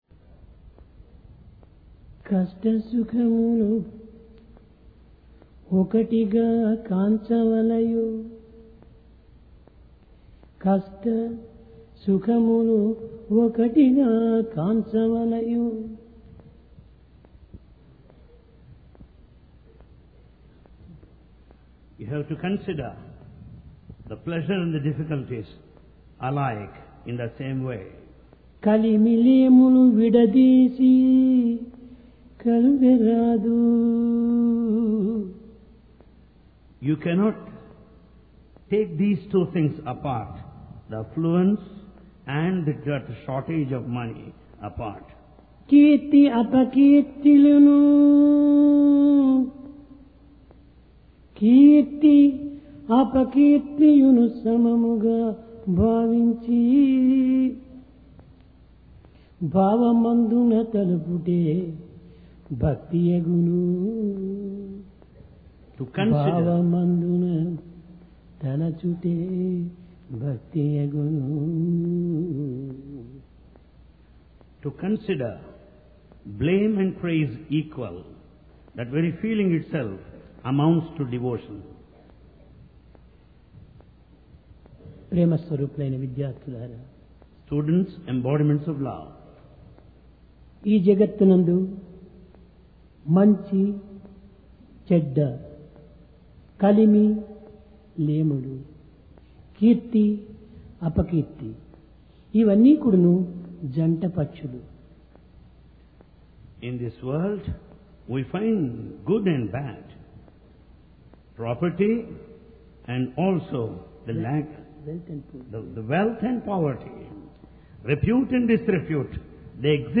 PRASHANTI VAHINI - DIVINE DISCOURSE 9 JULY, 1996